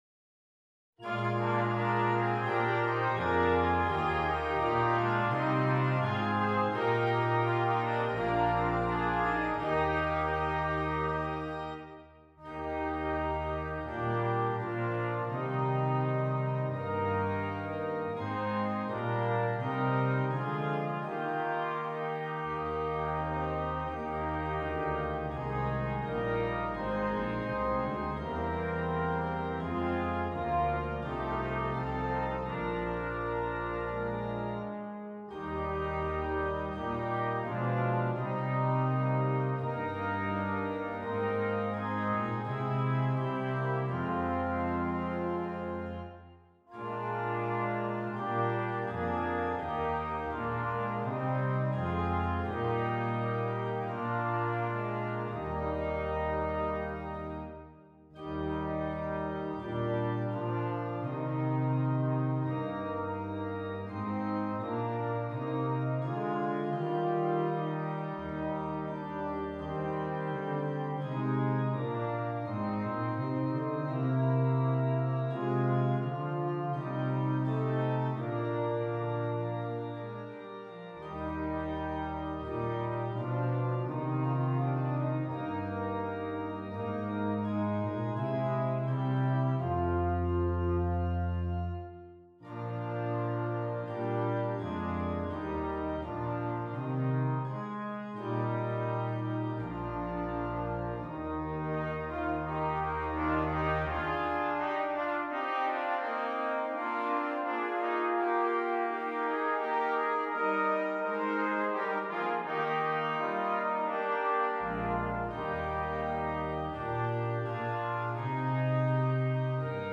Brass Quintet and Organ